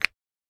click.mp3